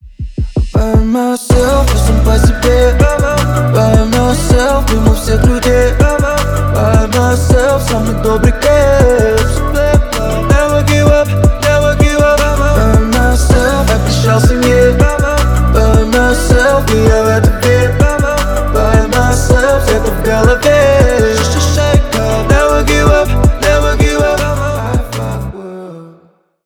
поп , клубные